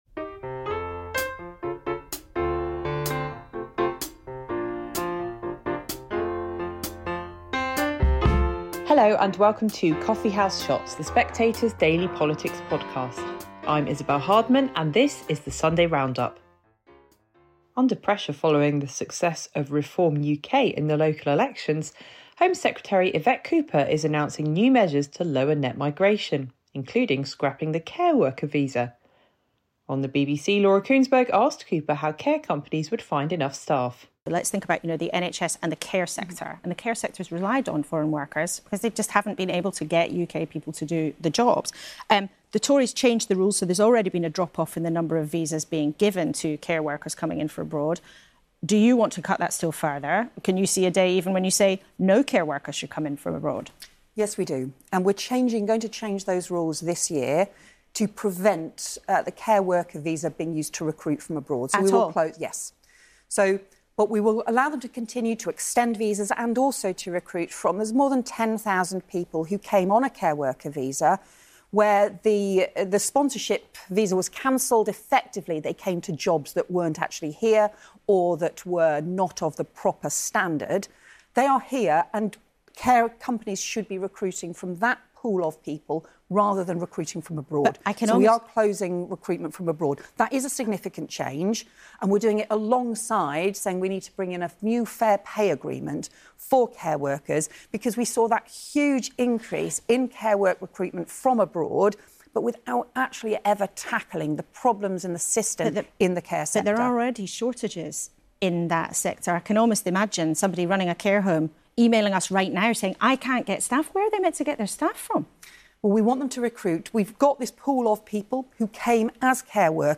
Isabel Hardman presents highlights from Sunday morning's political shows.